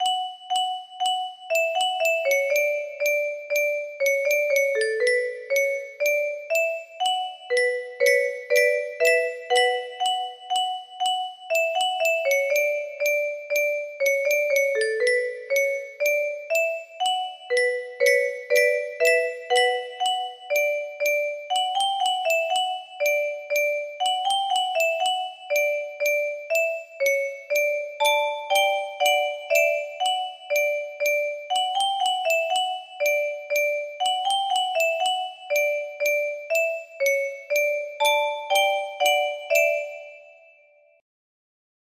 A Breton Melody music box melody